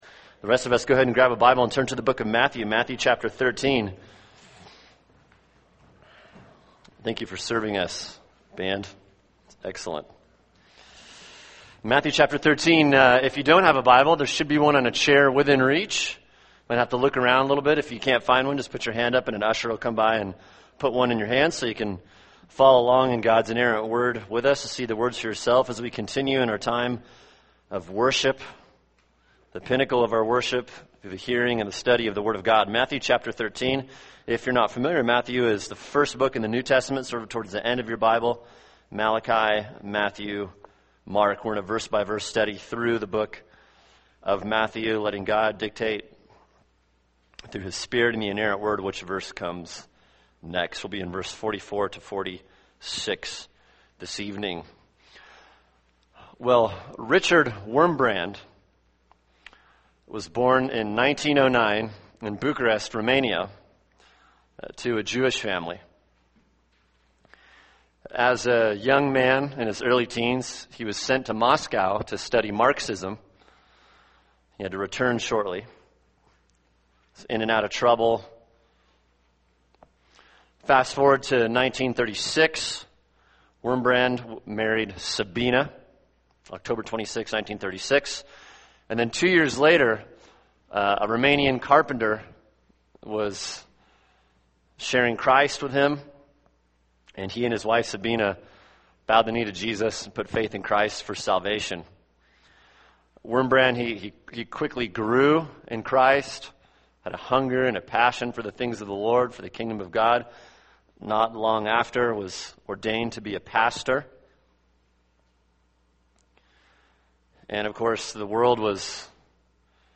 [sermon] Matthew 13:44-46 – The Unmatched Value of Salvation | Cornerstone Church - Jackson Hole